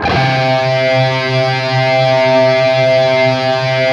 LEAD C 2 LP.wav